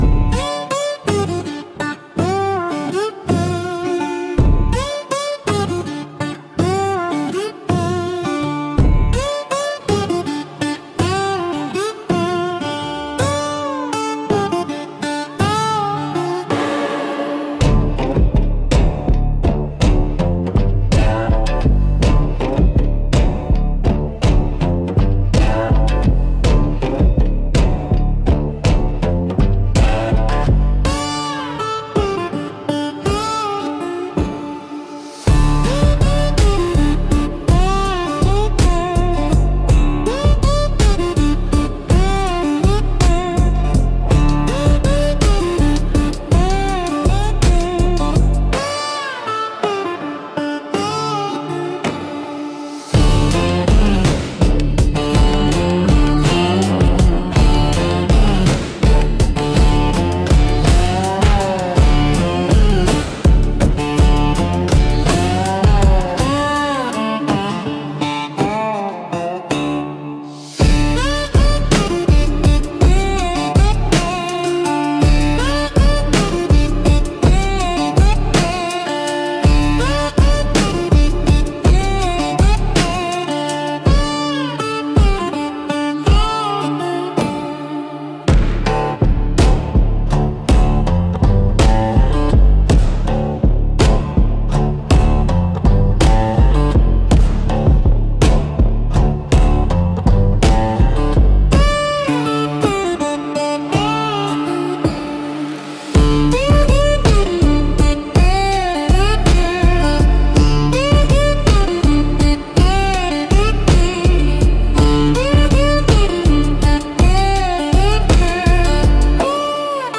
Instrumental -2.43 Lullaby for Ghosts